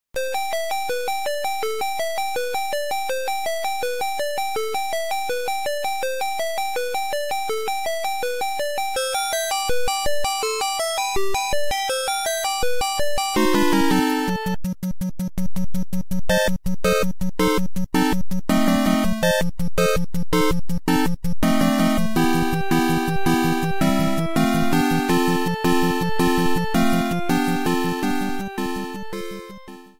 Overture theme